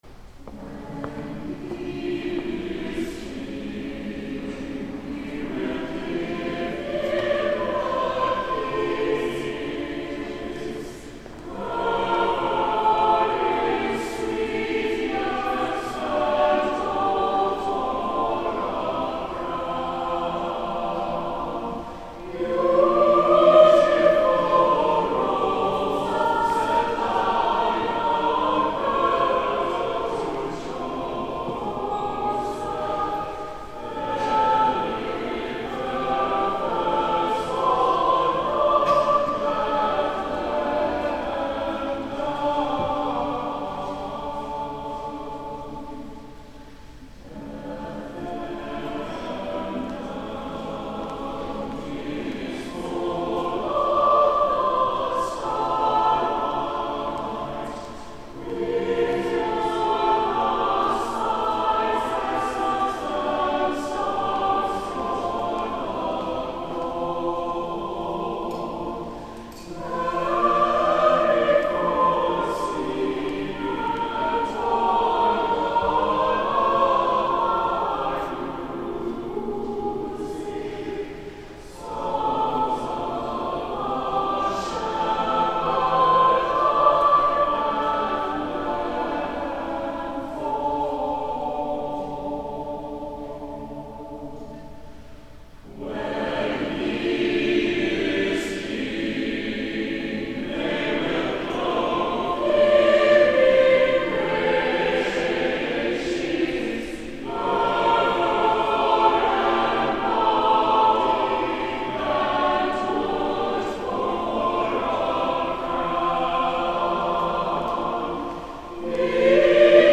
First Sunday after Christmas Day
Holy Eucharist
Cathedral Choir
Offering - Peter Warlock - Bethlehem Down